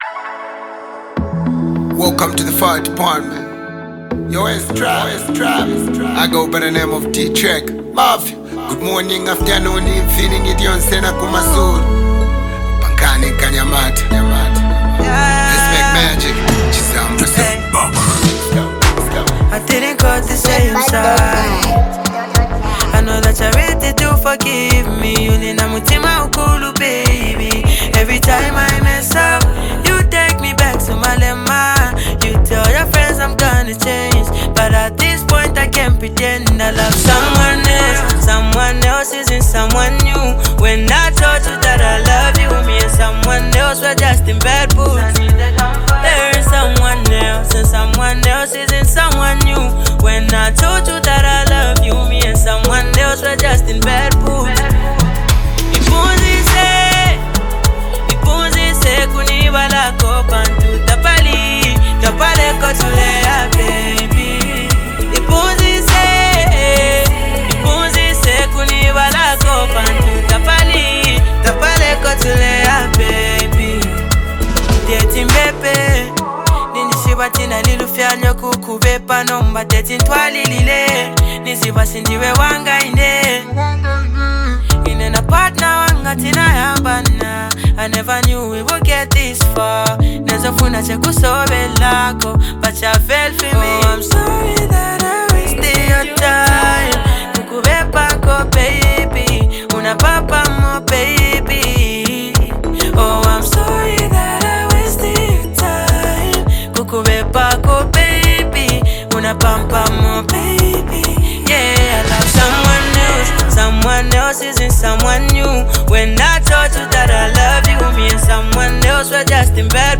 Genre: Afrobeats
Category: Zambian Music